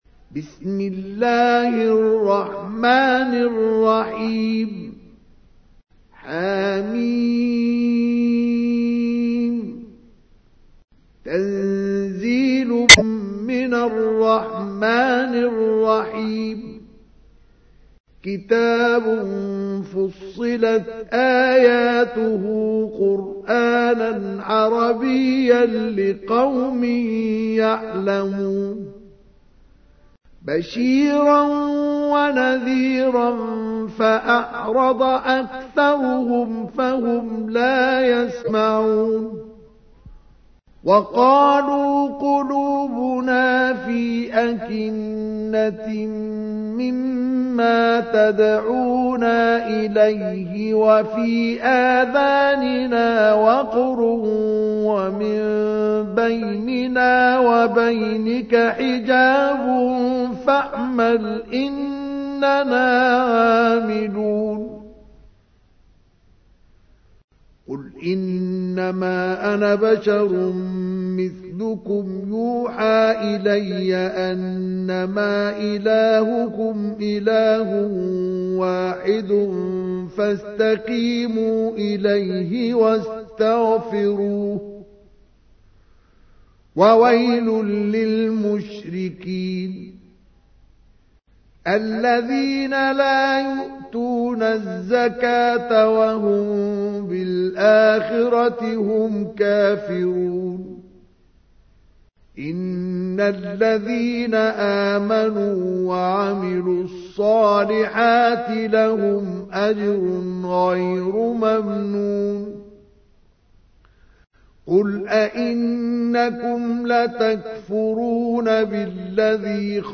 تحميل : 41. سورة فصلت / القارئ مصطفى اسماعيل / القرآن الكريم / موقع يا حسين